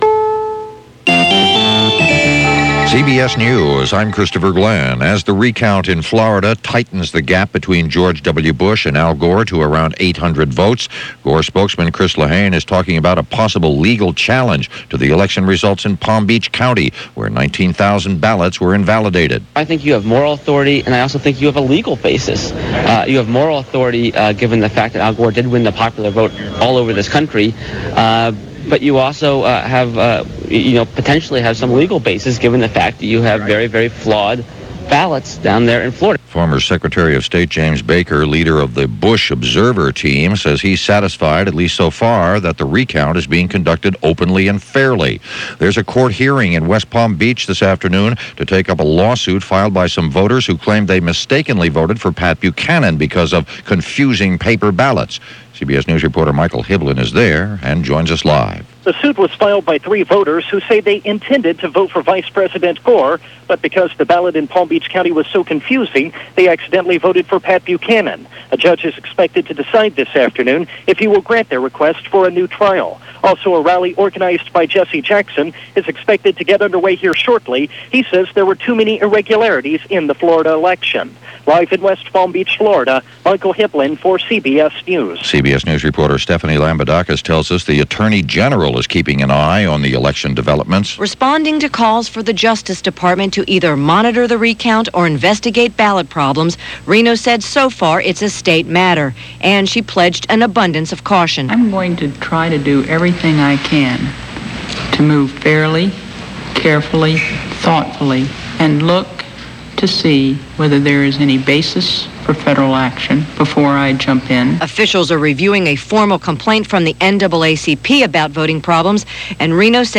November 9, 2000 – News – reports – World News Roundup – CBS Radio – Gordon Skene Sound Collection –